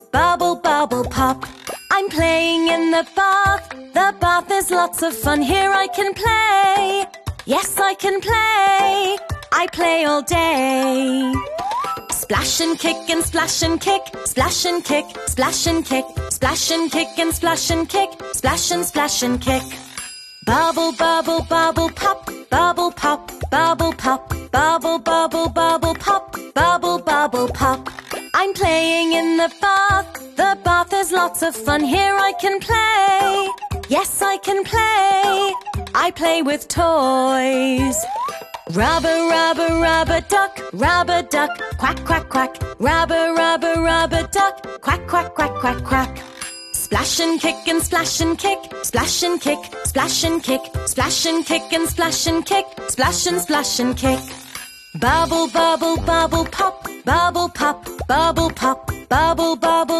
Rubber Rubber Rubber Duck Quack